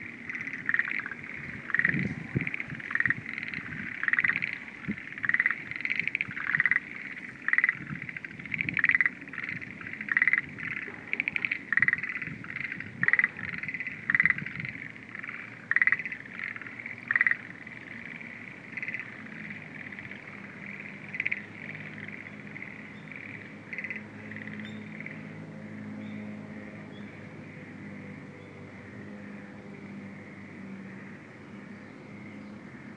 Frogs